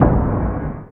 50 KIK NSE-L.wav